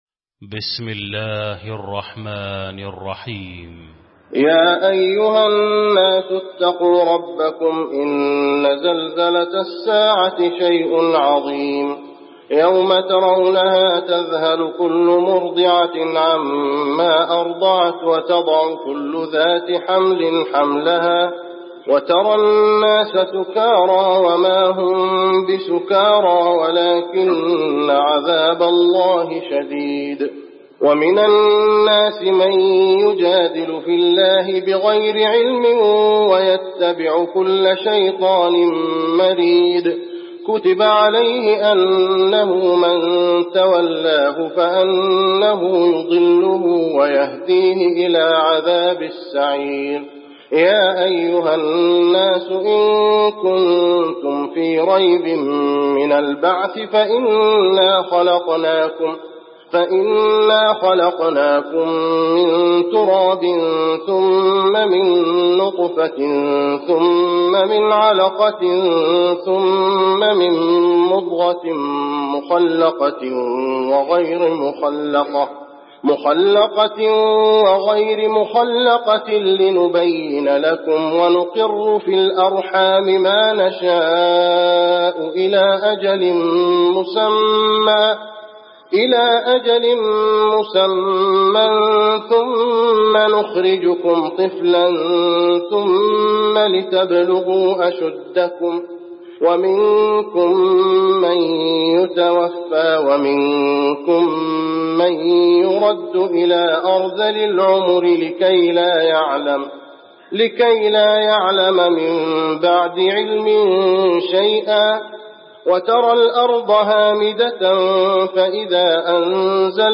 المكان: المسجد النبوي الحج